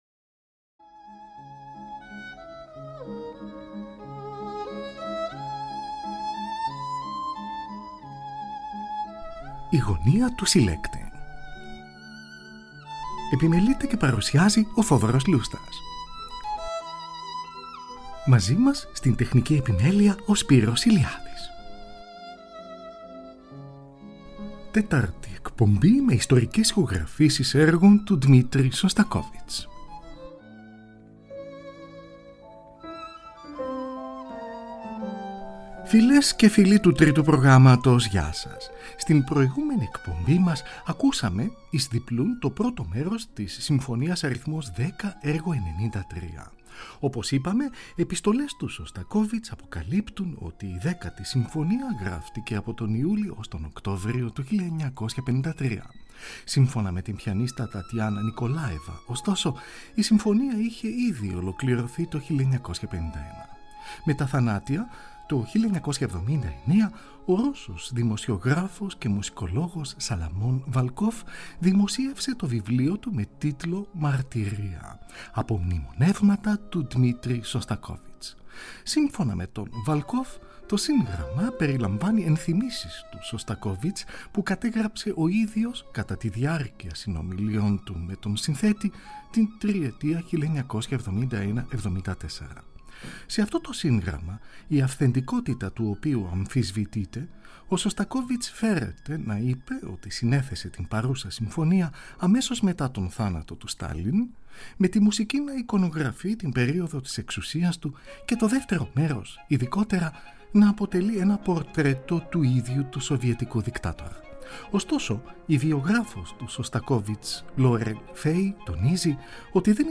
για πιάνο με τέσσερα χέρια
Στην ορχηστρική εκδοχή
Ιστορικες Ηχογραφησεις